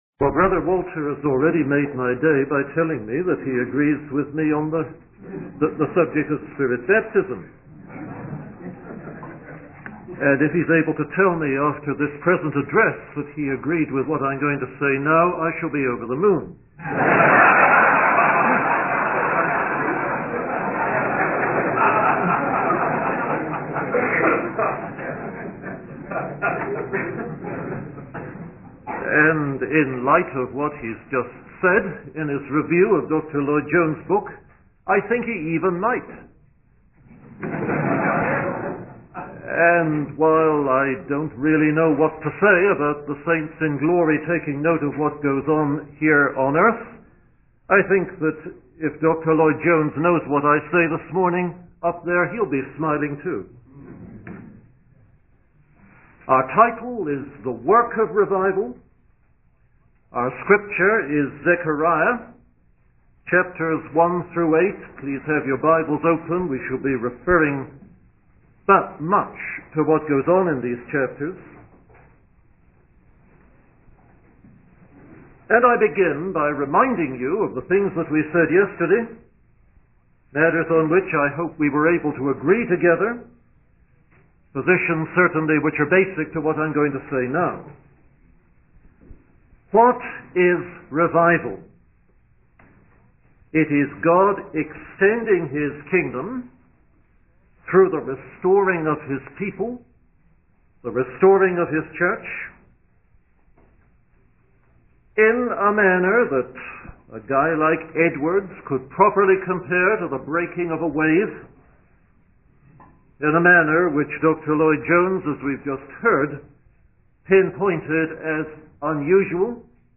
In this sermon, the preacher discusses the concept of revival and the pouring out of God's Spirit. He emphasizes the importance of practicing justice, mercy, and compassion towards others, as well as speaking the truth and rendering sound judgment.